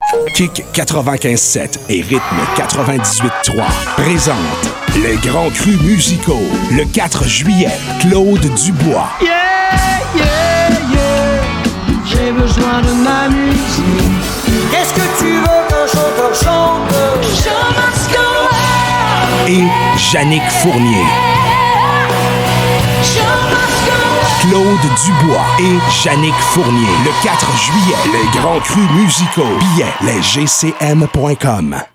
PROMO RADIO CLAUDE DUBOIS & JEANICK FOURNIER
promo-radio-les-grands-crus-4-juillet-2025.mp3